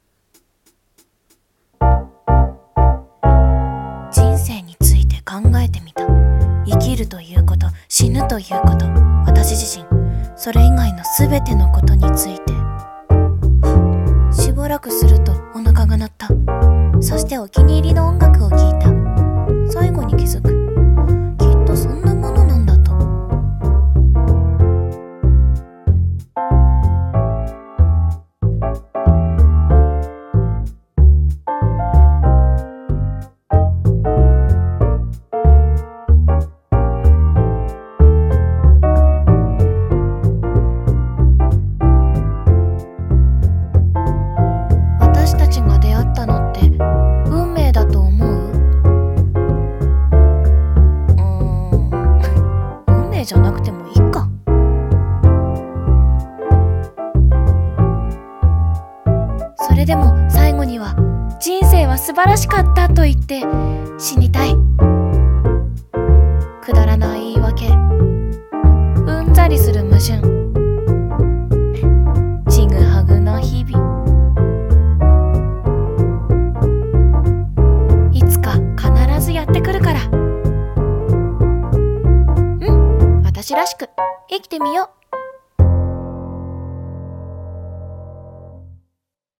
【声劇】About Life